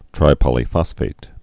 (trī-pŏlē-fŏsfāt)